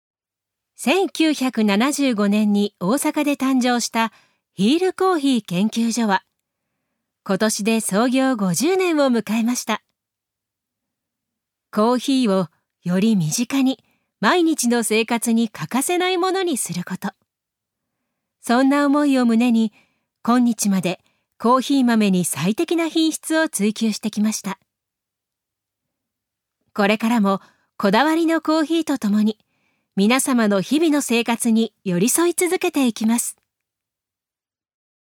女性タレント
ナレーション２